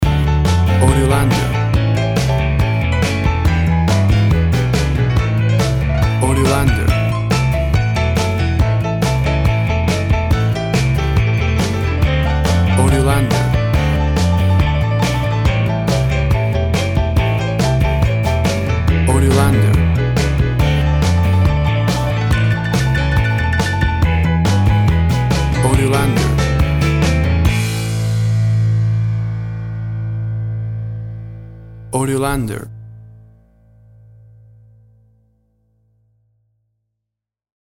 WAV Sample Rate 16-Bit Stereo, 44.1 kHz
Tempo (BPM) 142